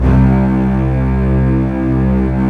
STR STRING00.wav